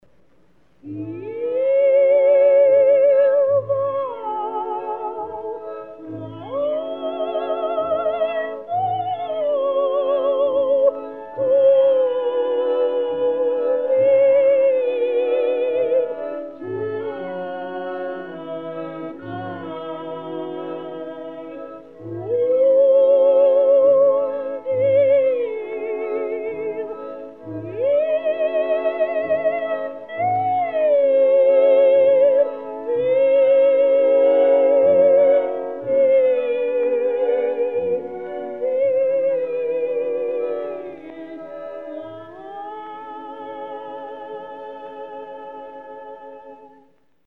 This CD is a collection of 18 restored songs.
was an Australian dramatic soprano